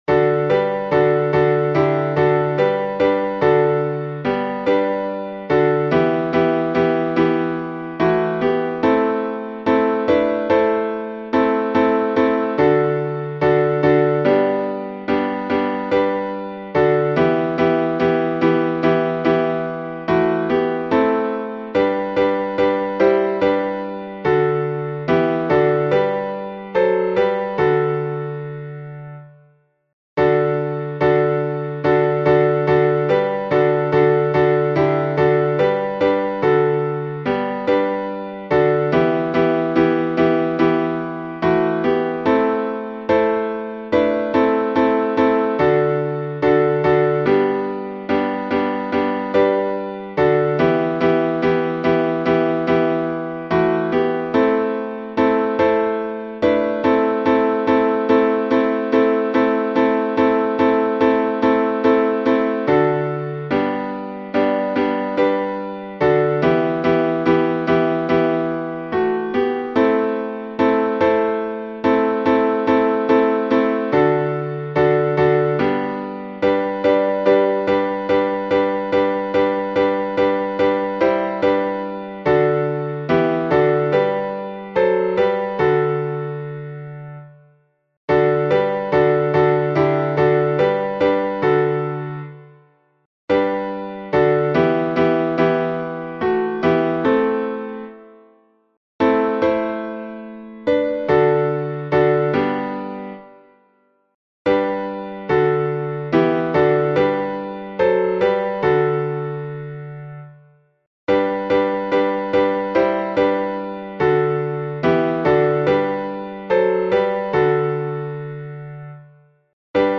глас 2